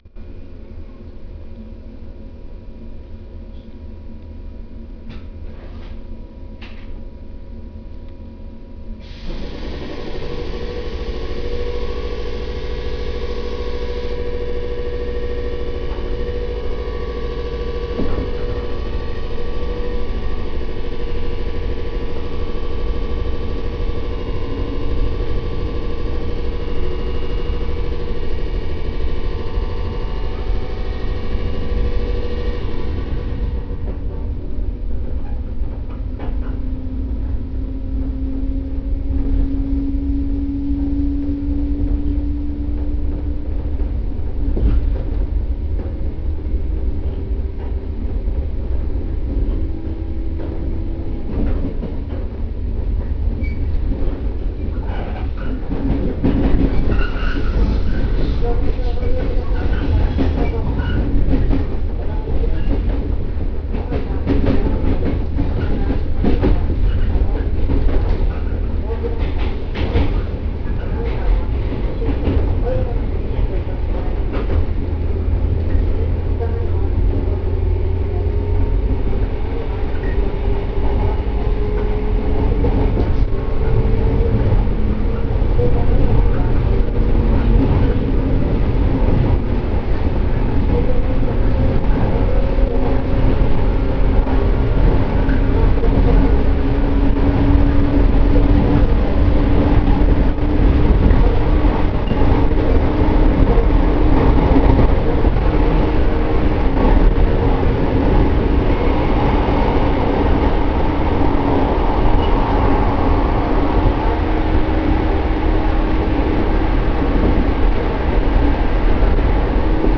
・115系走行音
【両毛線】桐生→小俣（5分44秒：1.82MB）
ごく普通の抵抗制御。113系や415系でもよく聞ける標準的な音です。